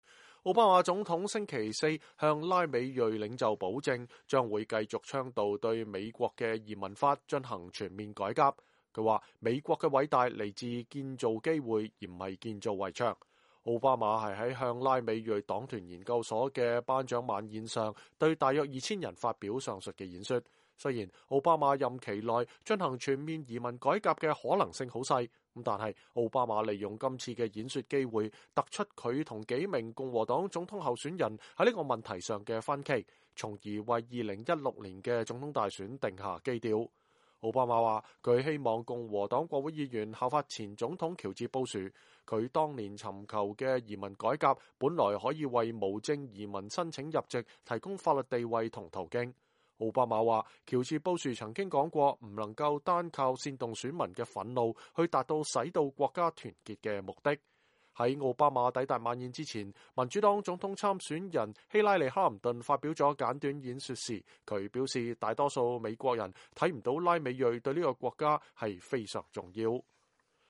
他說，美國的偉大來自於建造機會，而不是建造圍牆。奧巴馬是在向拉美裔黨團研究所的頒獎晚宴上對大約2000人發表這番演說。